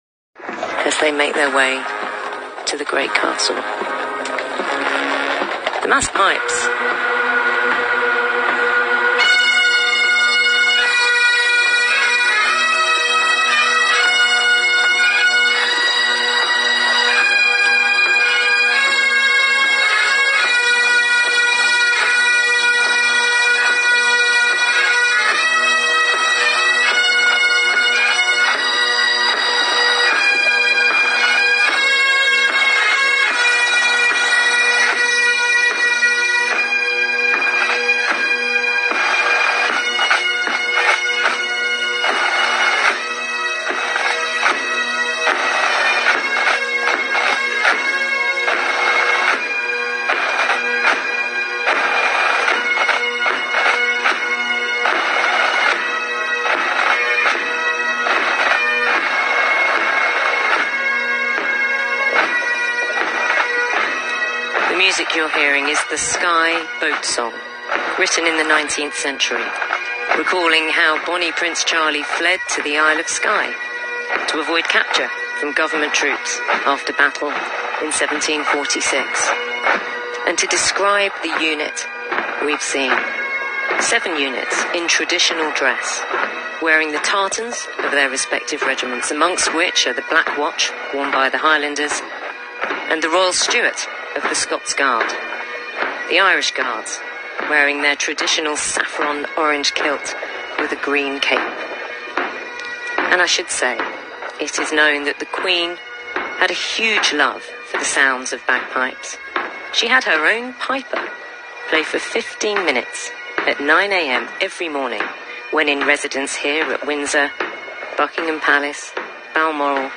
THE PIPES N DRUMS PLAYING THE SKYE BOAT SONG AT THE FUNERAL OF QUEEN ELIZABETH II